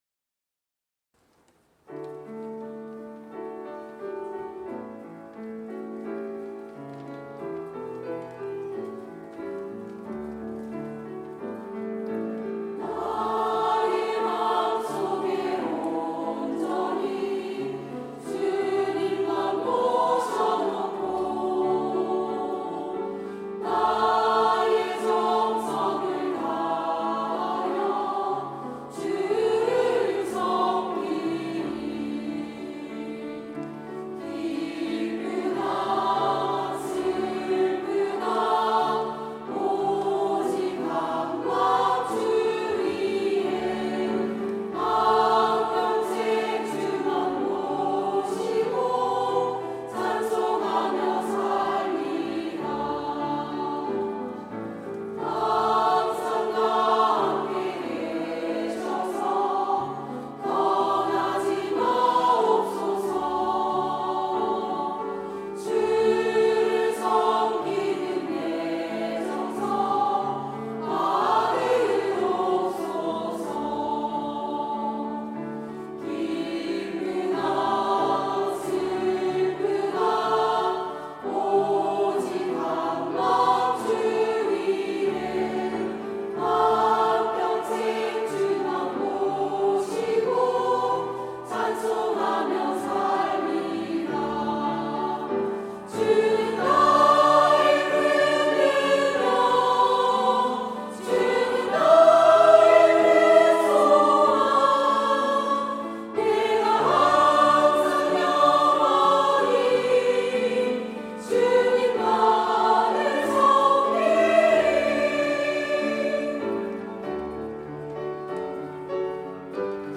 온세대예배 찬양대